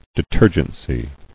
[de·ter·gen·cy]